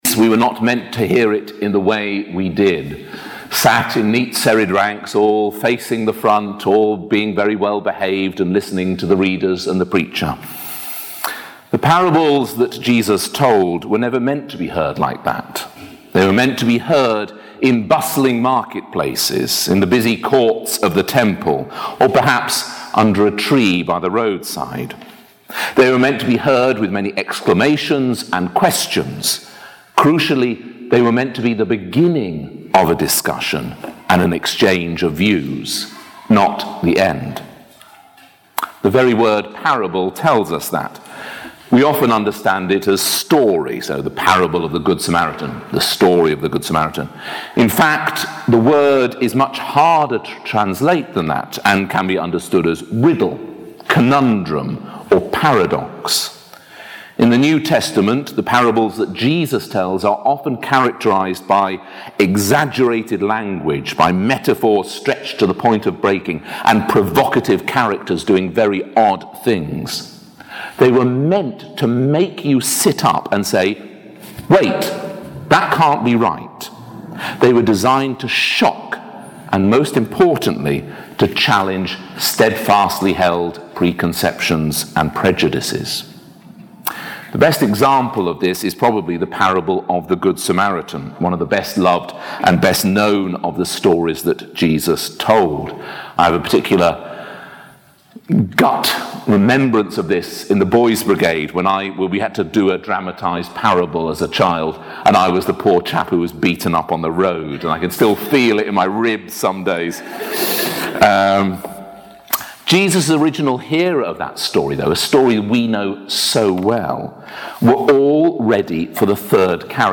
In his first service at Hounslow